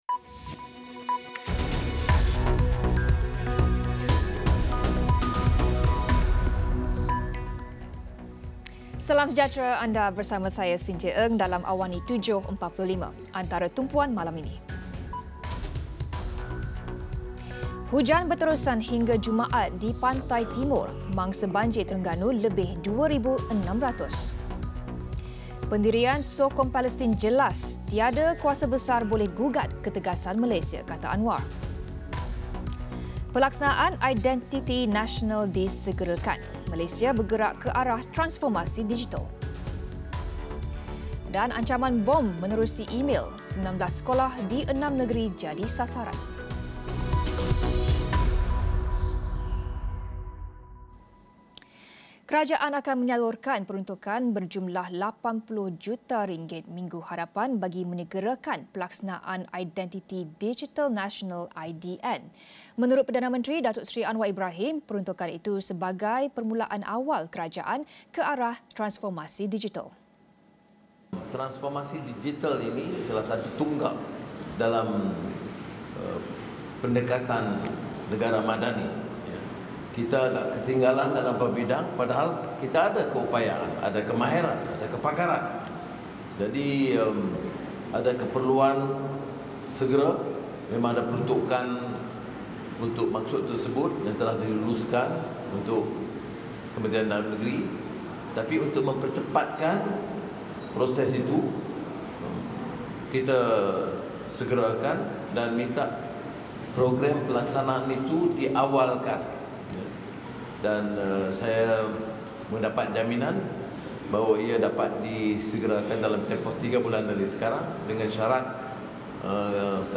Laporan berita